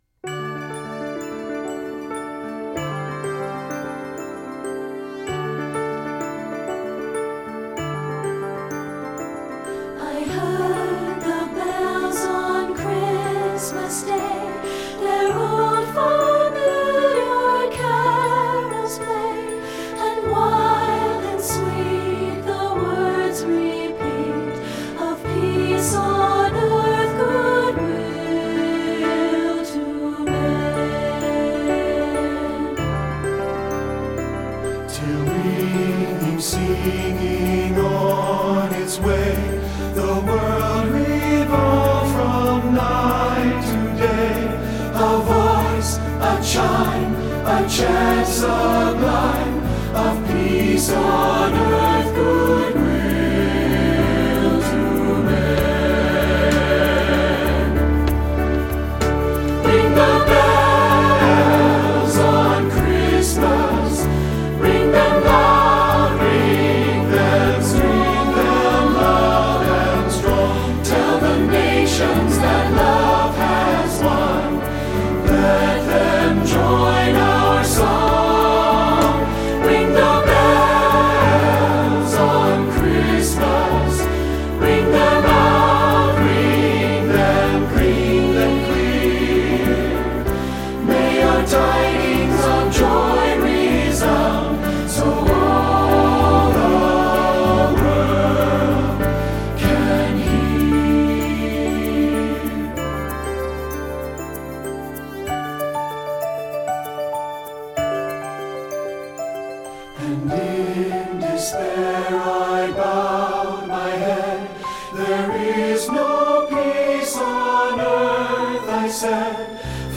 secular choral
SATB